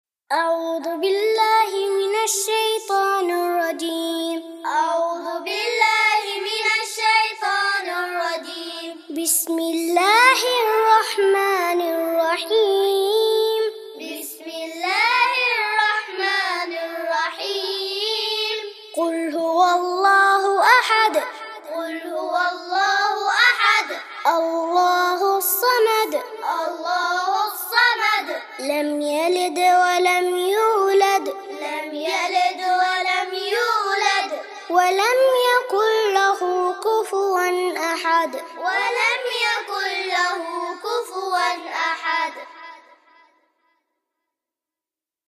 الاخلاص (تعليمي للصغار) - لحفظ الملف في مجلد خاص اضغط بالزر الأيمن هنا ثم اختر (حفظ الهدف باسم - Save Target As) واختر المكان المناسب